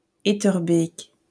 Etterbeek (French: [etœʁbek, -bɛk]